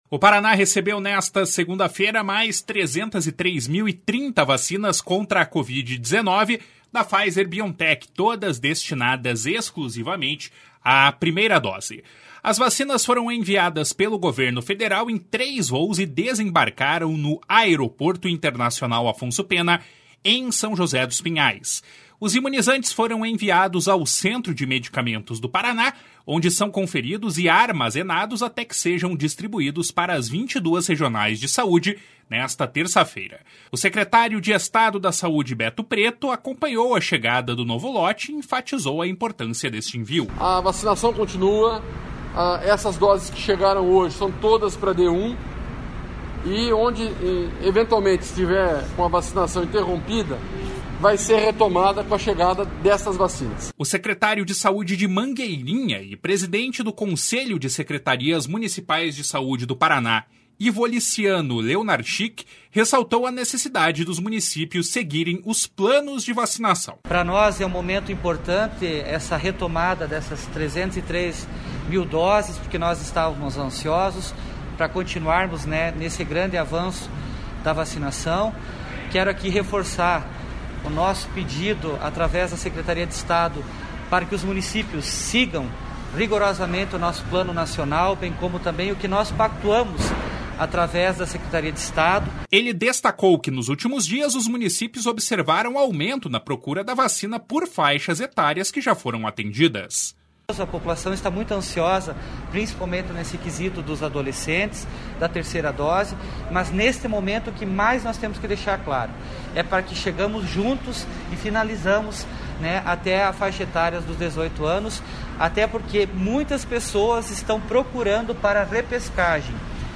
O secretário de Estado da Saúde, Beto Preto, acompanhou a chegada do novo lote e enfatizou a importância deste envio. // SONORA BETO PRETO // O secretário de Saúde de Mangueirinha e presidente do Conselho de Secretarias Municipais de Saúde do Paraná, Ivoliciano Leonarchik, ressaltou a necessidade dos municípios seguirem os planos de vacinação.